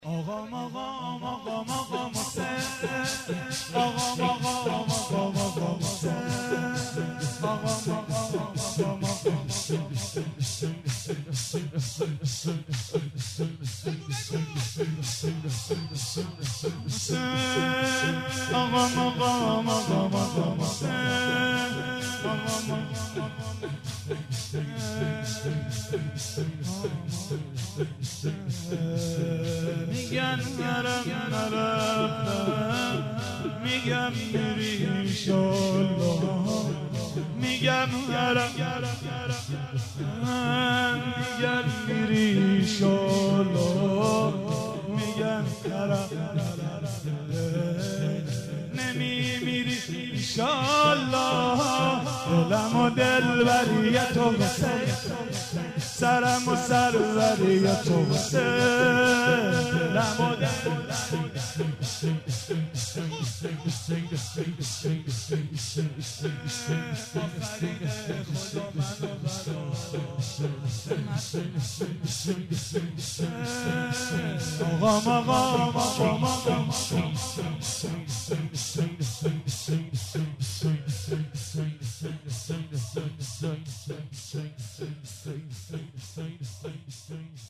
صوت مداحی
مراسم هفتگی هیئت سپهسالار